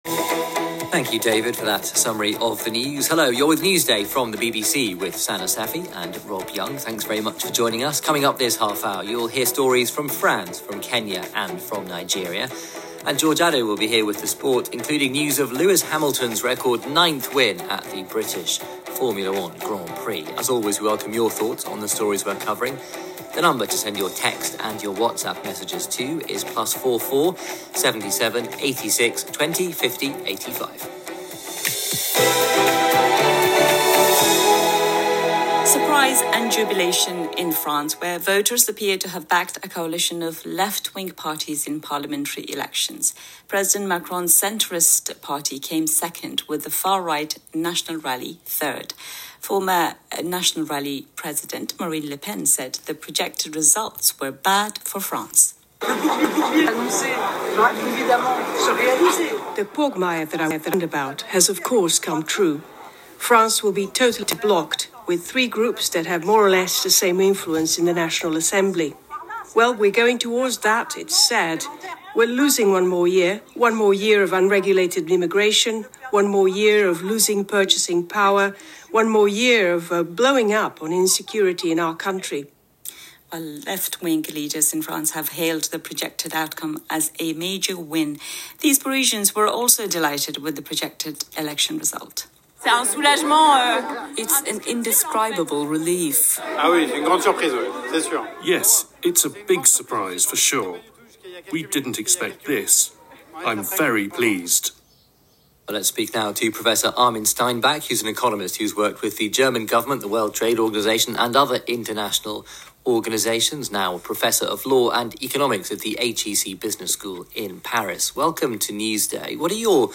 Newsday French Elections Interview | BBC 09.08.2024